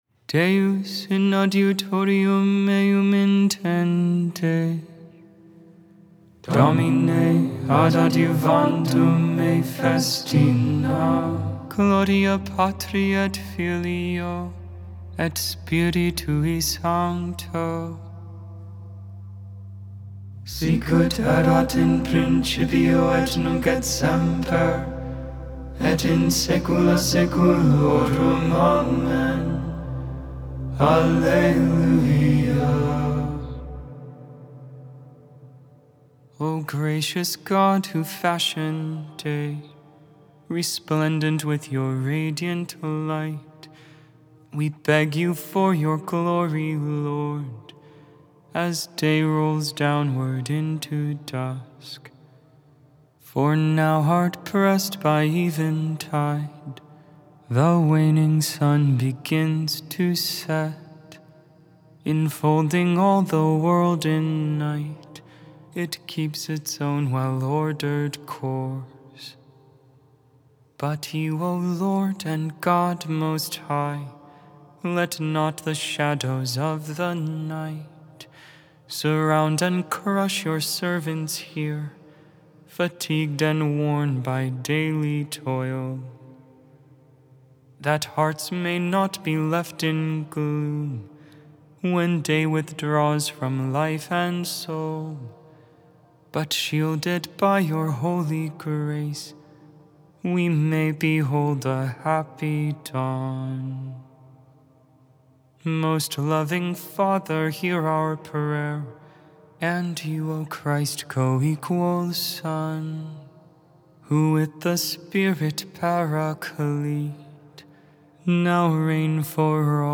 6.13.24 Vespers, Thursday Evening Prayer
Ambrosian Hymn - Frankish Hymnal c. 700-800 AD.